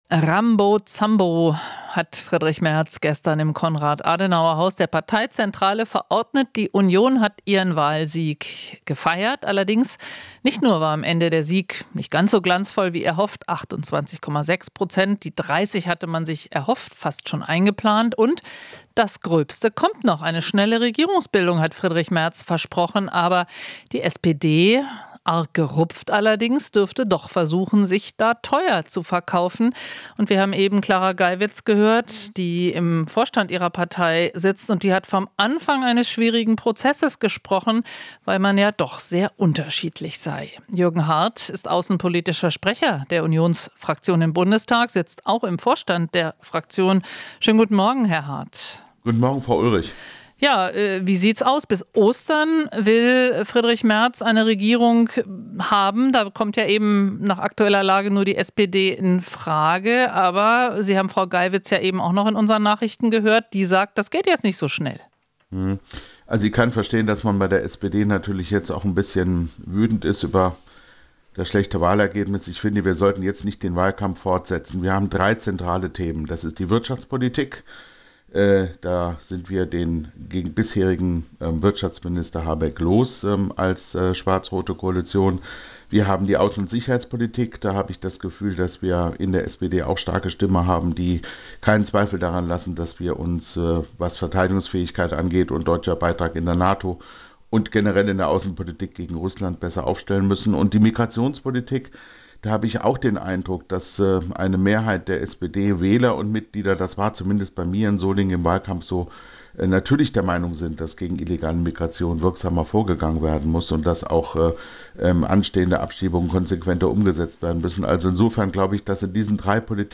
Interview - Hardt (CDU) sieht "große Schnittmengen mit der SPD"